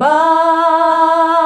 BAH UNI E.wav